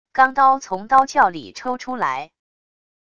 钢刀从刀鞘里抽出来wav音频